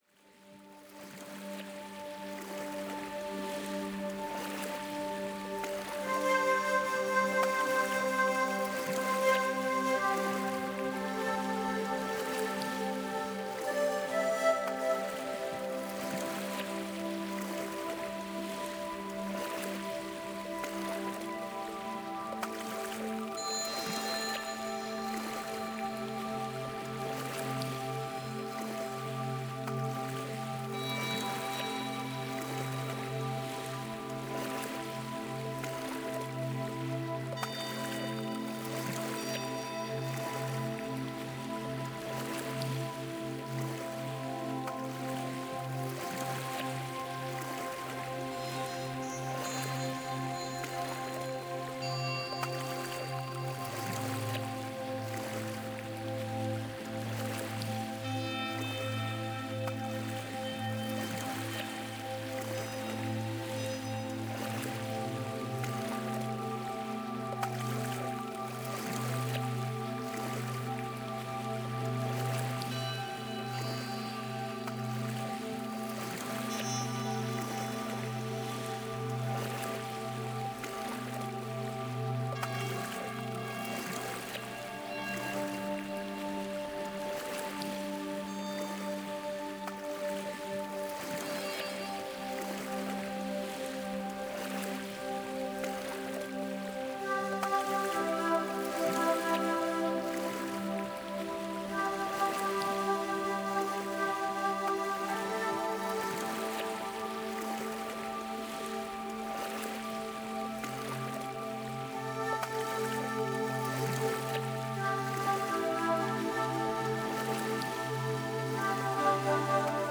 Genre: New Age, Ambient, Relax.